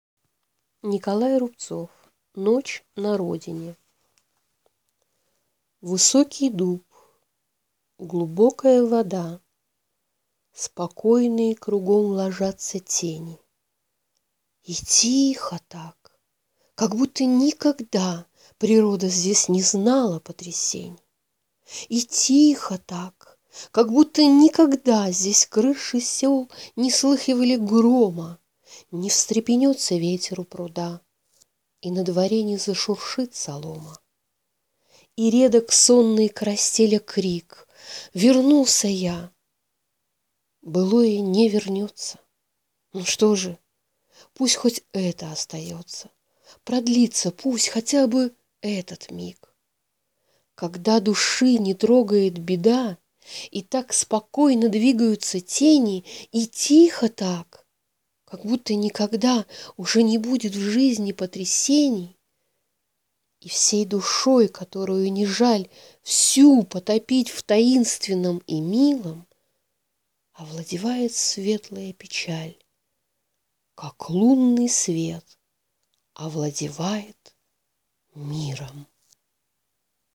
noch--na-rodine(stihi)-rubtsov-n..mp3